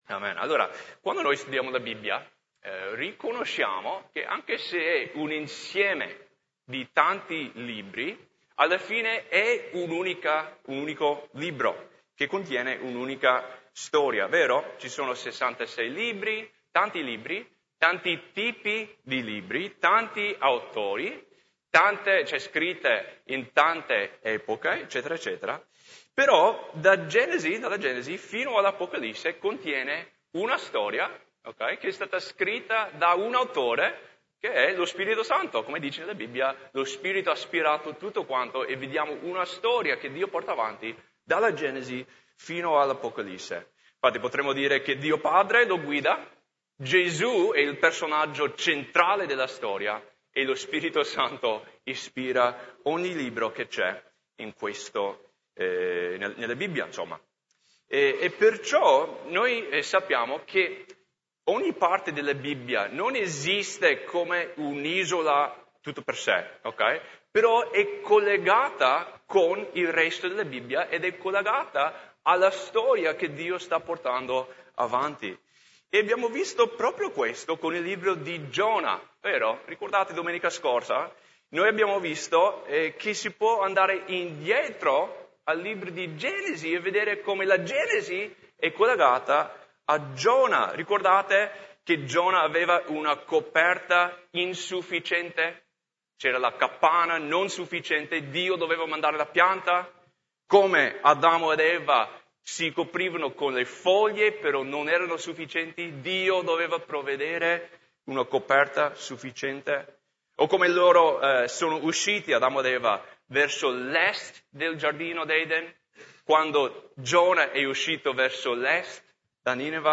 Messaggio di Domenica 04 Agosto